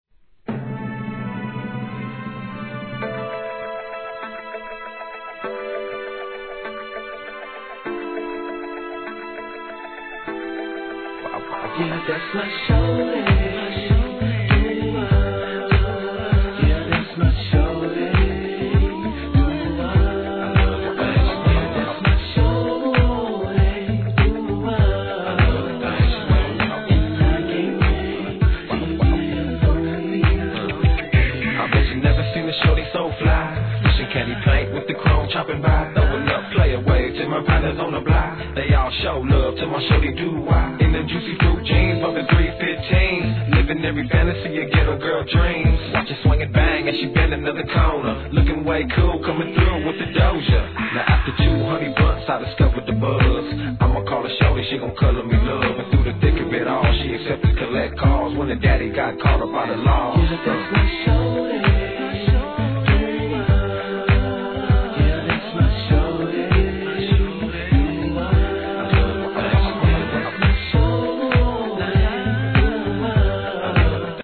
HIP HOP/R&B
同路線のメロ〜作でこちらも人気!!